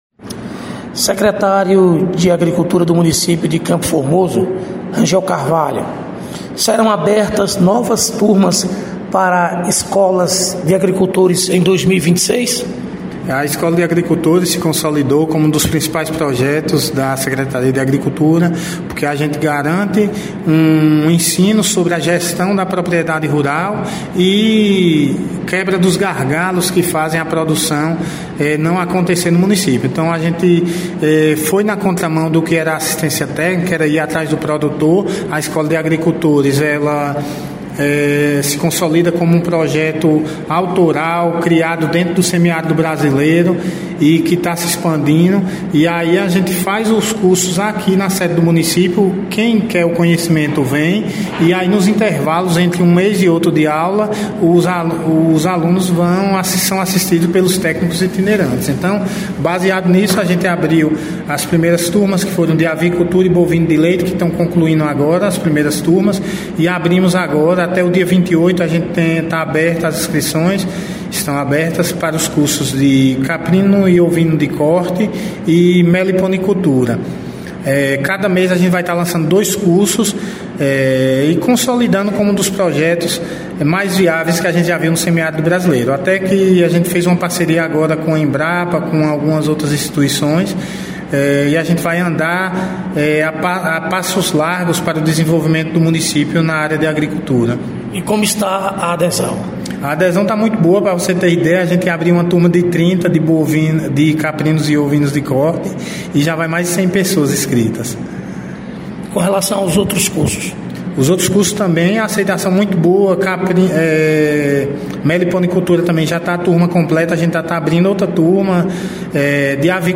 Secretário de agricultura de CFormoso, Rangel Carvalho – Inscrições para a formação de turmas para agricultores rurais